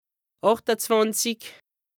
2APRESTA_OLCA_LEXIQUE_INDISPENSABLE_HAUT_RHIN_109_0.mp3